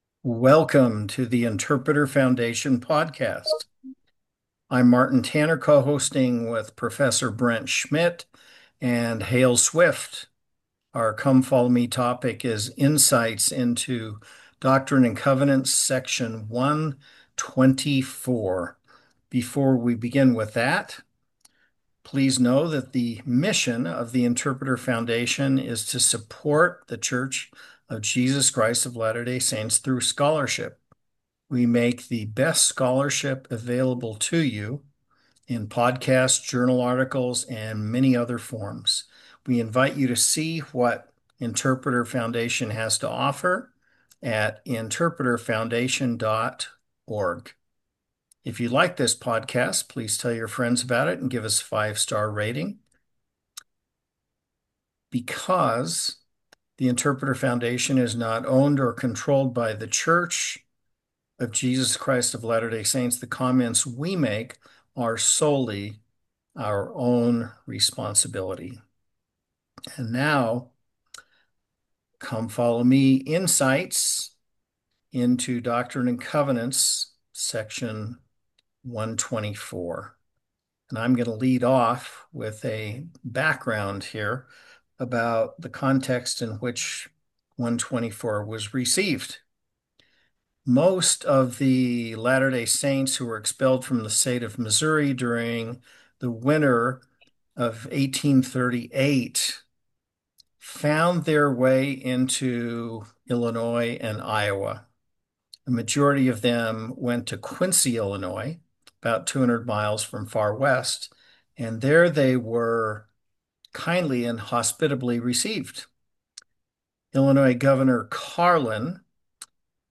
Podcast: Download The Interpreter Foundation Podcast is a weekly discussion of matters of interest to the hosts and guests.